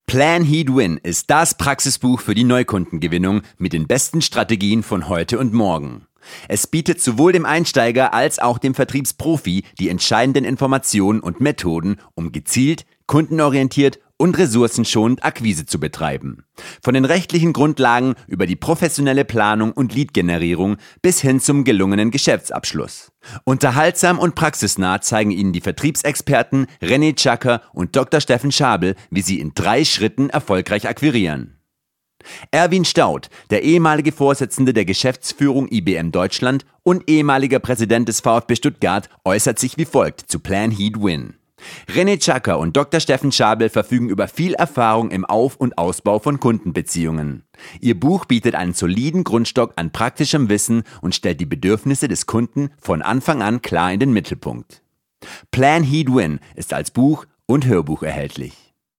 Audiobuch-Trailer-kurz.mp3